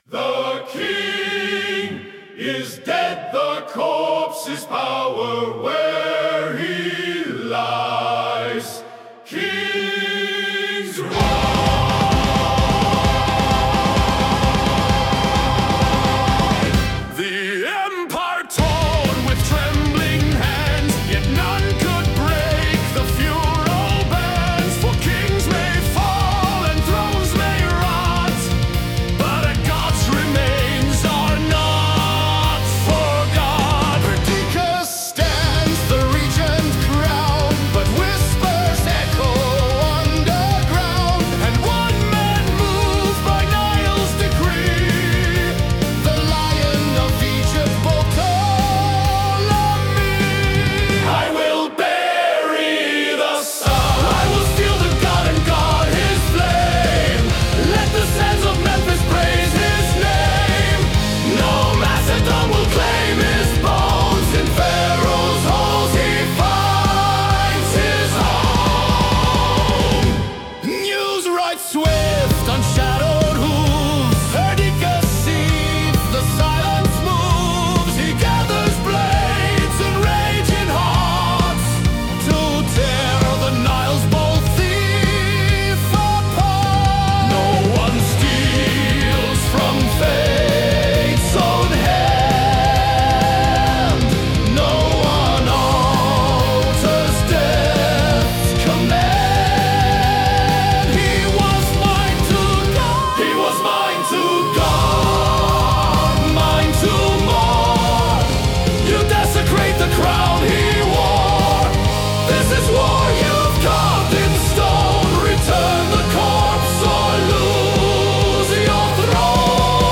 power metal epic